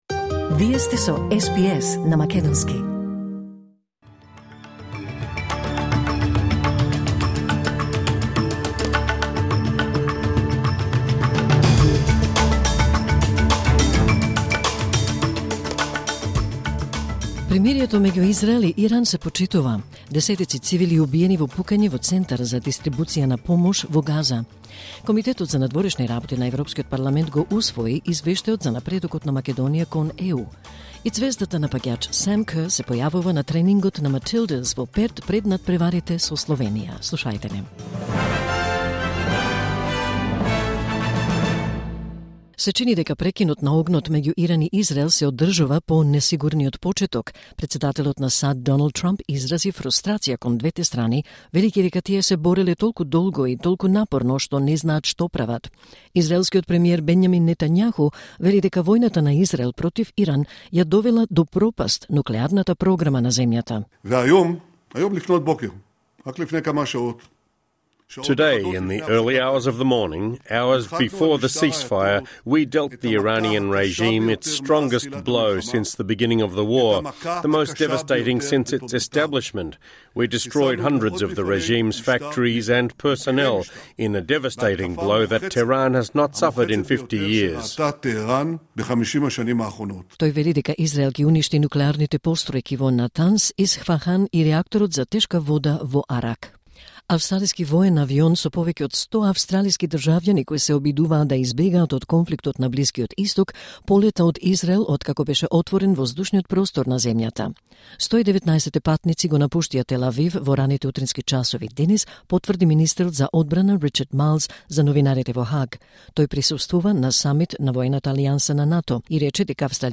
Вести на СБС на македонски 25 јуни 2025